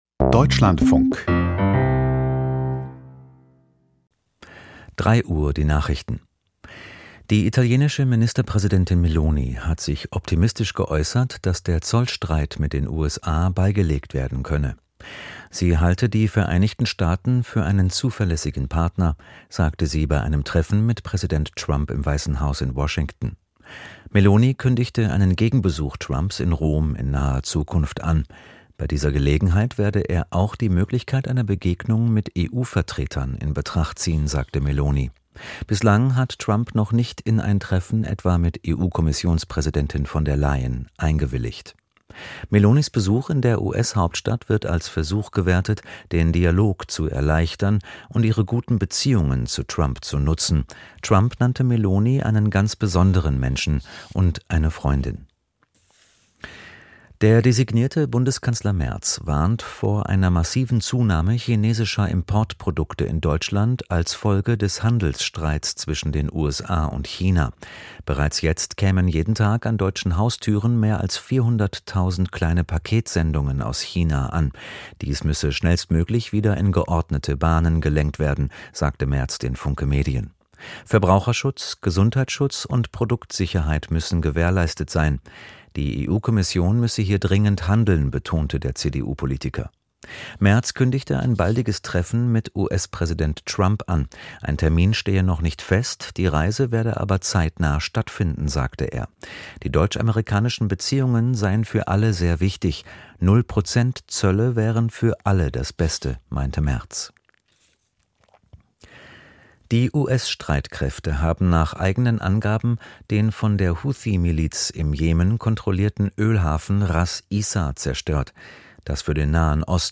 Die Deutschlandfunk-Nachrichten vom 18.04.2025, 03:00 Uhr